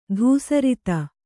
♪ dhūsarita